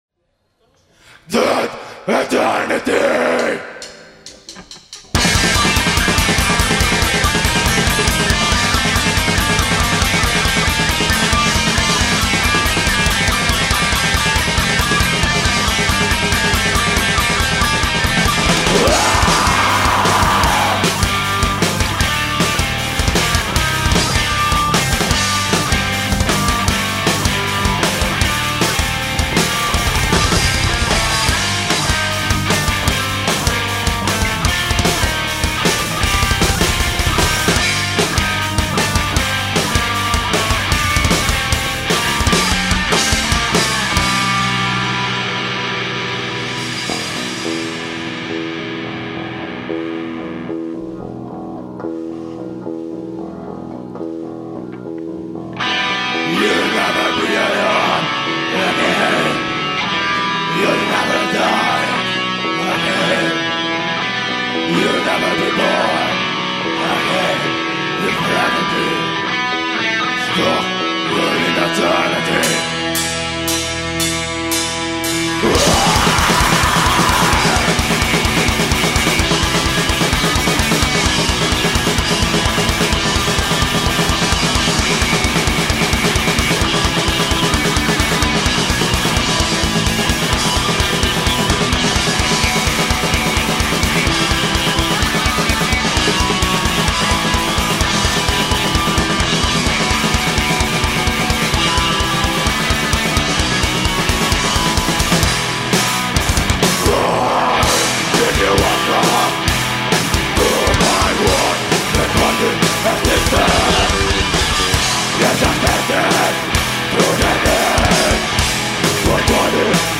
Registrazioni dal vivo
5'16" 4,94MB Comunità Giovanile